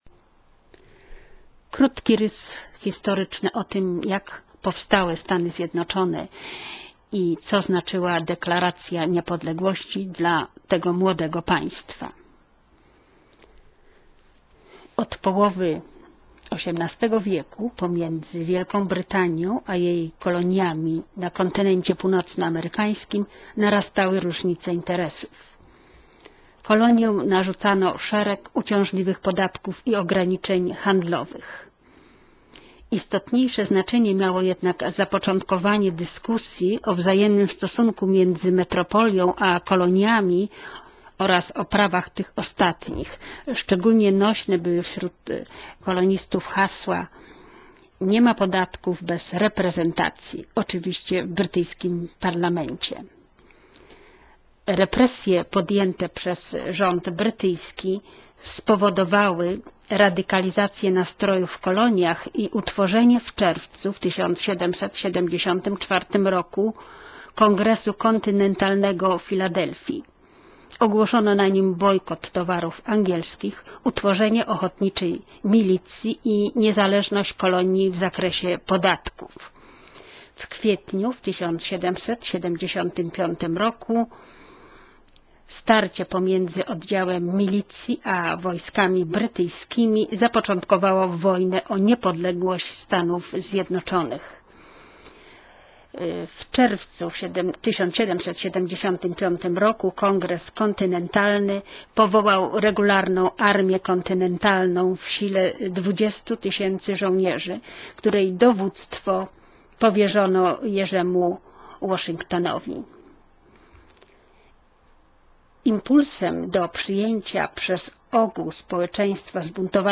Nagranie archiwalne z 4 lipca 2009 r. Polsko- Amerykańskim i Amerykańsko-Polskim słuchaczom--Happy 4th of July!